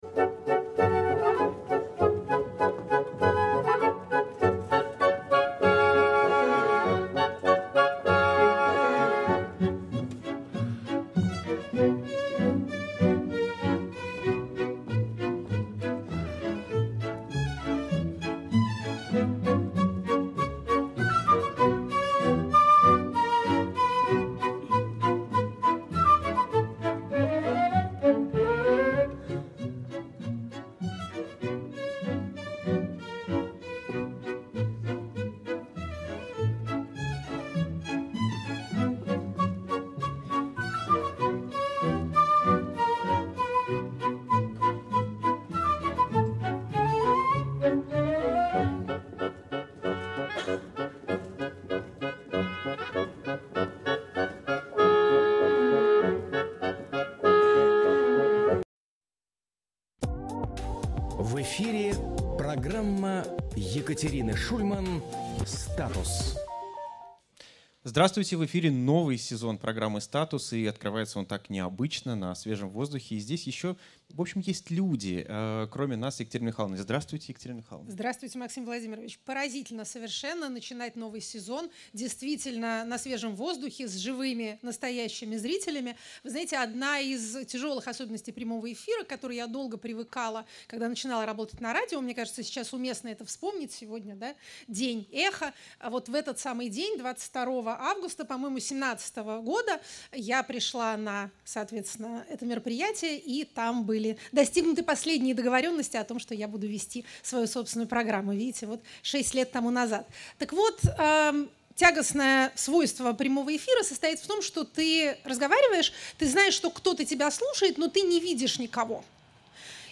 И открывается он так необычно — на свежем воздухе.